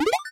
Accept9.wav